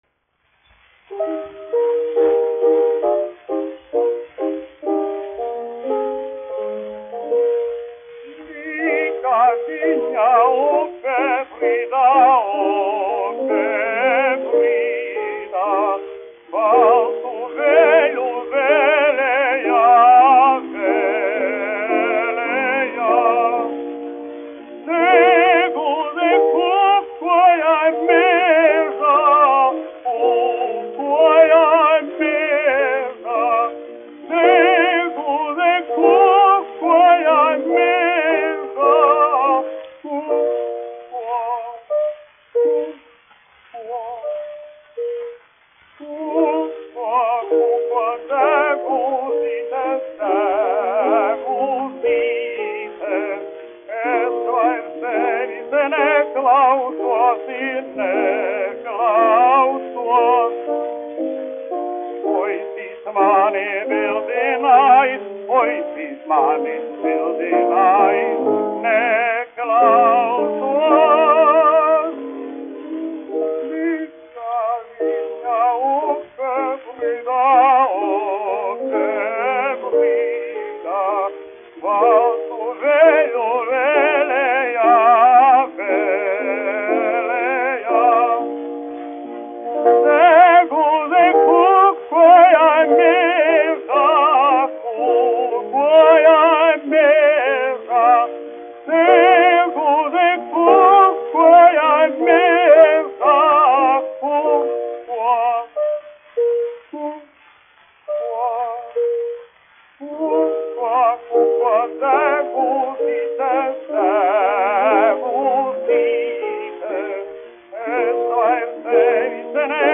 1 skpl. : analogs, 78 apgr/min, mono ; 25 cm
Dziesmas (augsta balss) ar klavierēm
Skaņuplate
Latvijas vēsturiskie šellaka skaņuplašu ieraksti (Kolekcija)